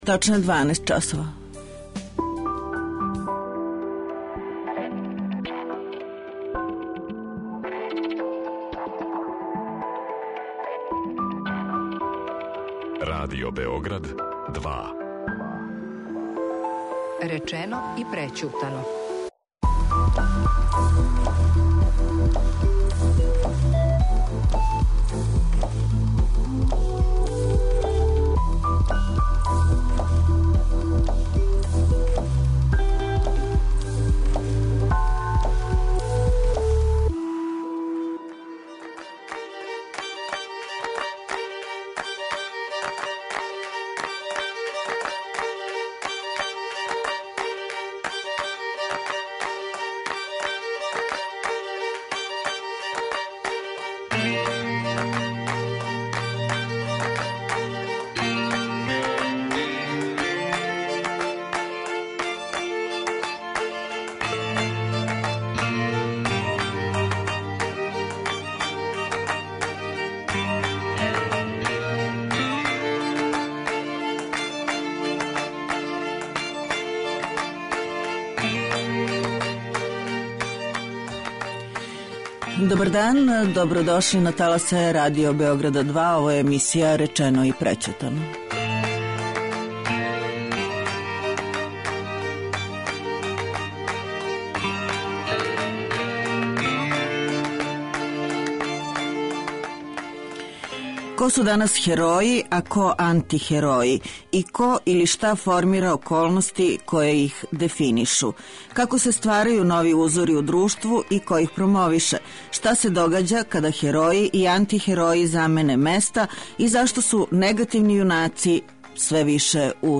Гост у студију Радио Београда 2